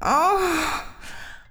Vox (MetroMoan2).wav